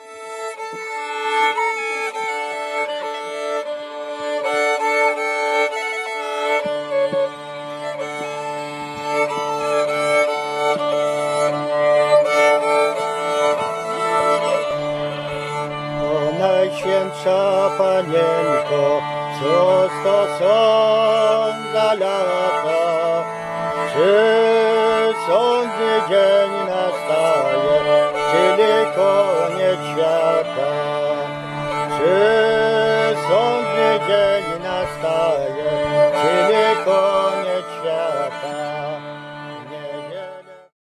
Pie¶ń o bitwie pod Wiedniem A historical ballad on the victory of king Jan Sobieski over Turks in Vienna, 1683
The CD contains archival recordings made in 1993-2007 in the area of western Roztocze (Lubelskie region) and its surrounding villages.
¶piew vocals